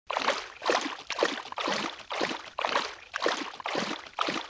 Title=mer_calme_04